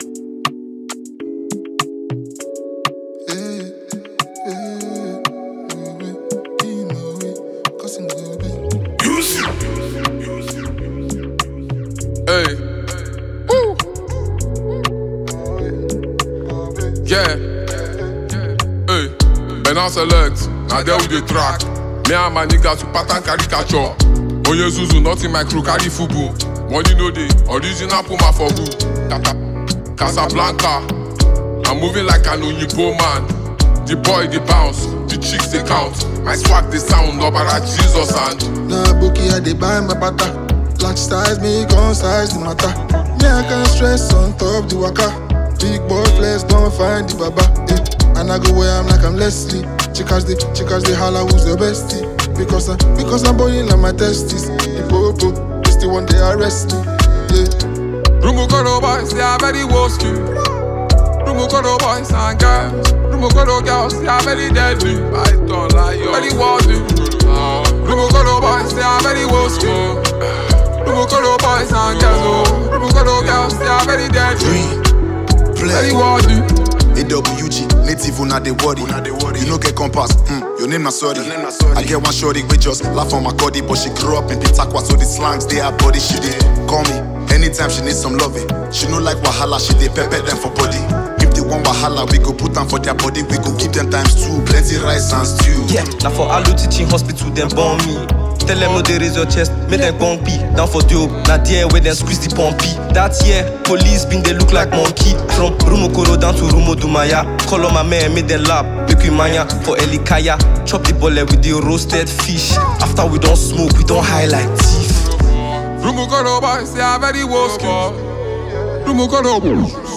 Genre: Afrobeats